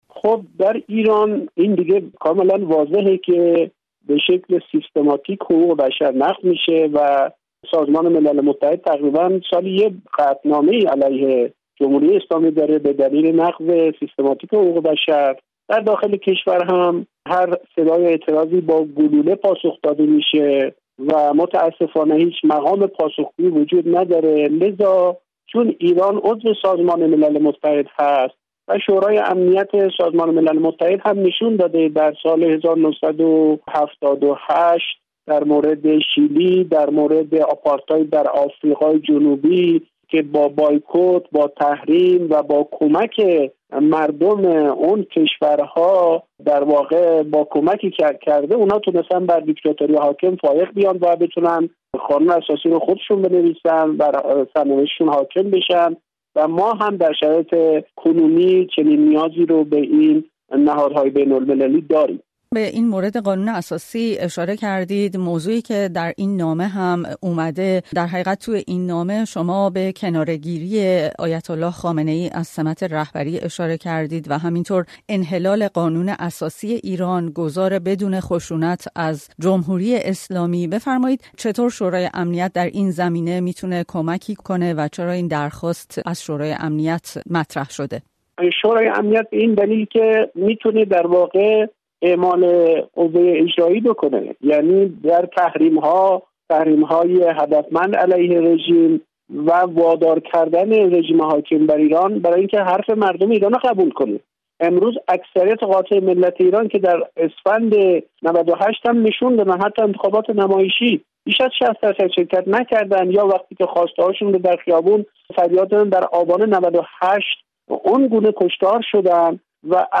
حشمت‌الله طبرزدی، یکی از امضاکنندگان نامه، در گفت‌وگو با رادیوفردا